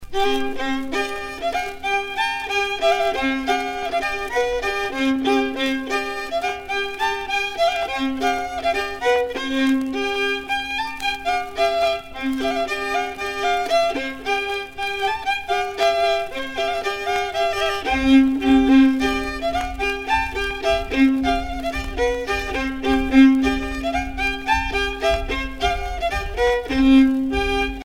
danse : troïka
Pièce musicale éditée